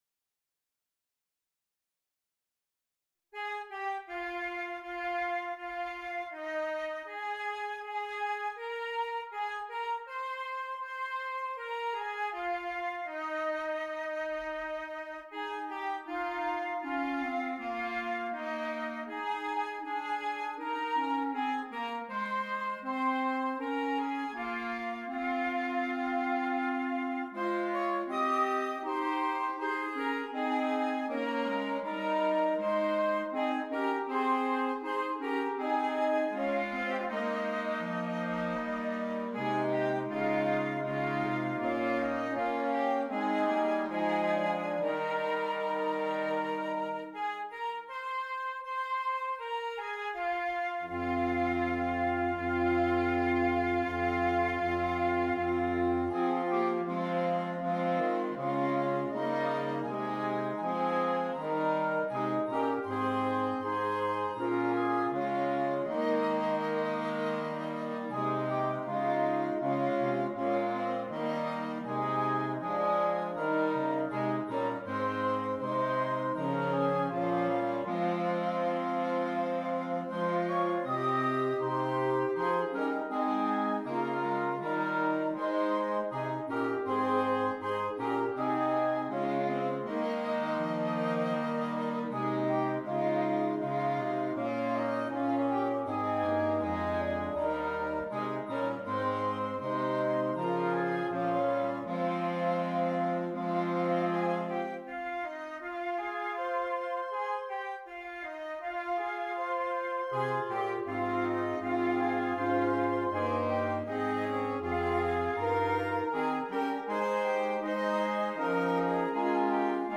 Interchangeable Woodwind Ensemble
Traditional English Folk Song